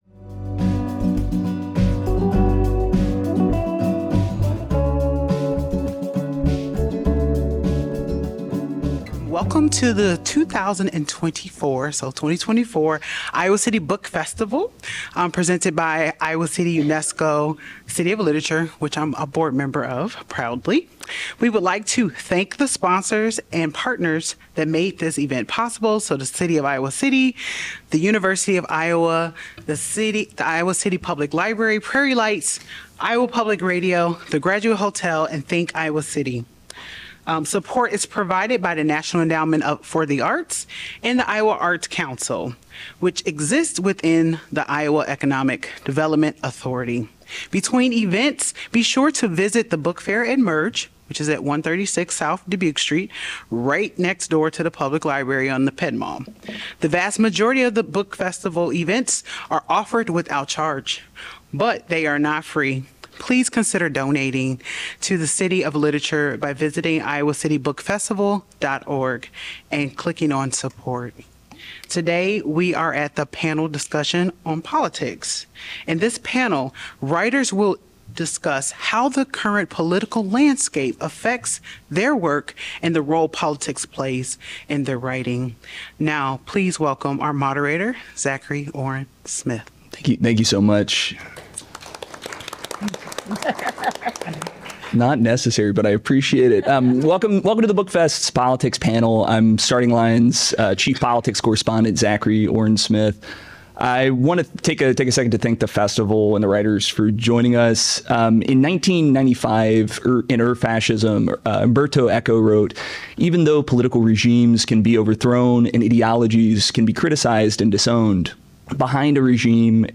Iowa City UNESCO City of Literature presents a panel discussion from its 2024 Iowa City Book Festival.